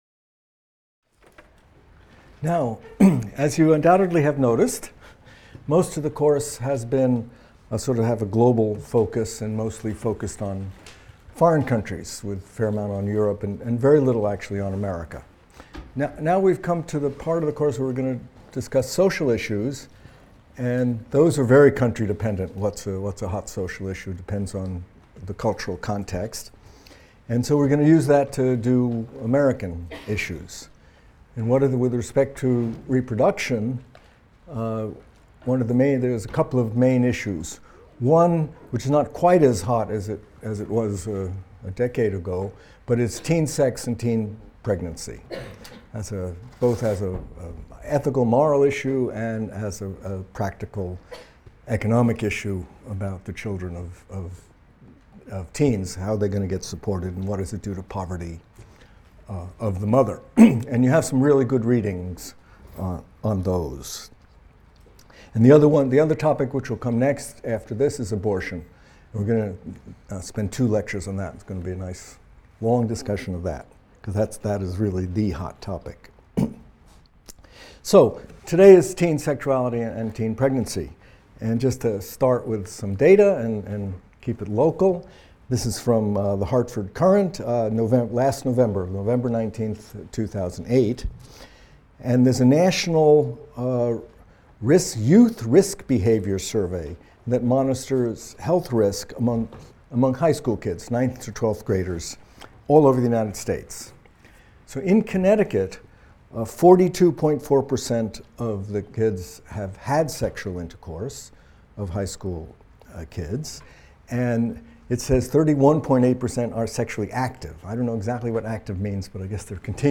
MCDB 150 - Lecture 20 - Teen Sexuality and Teen Pregnancy | Open Yale Courses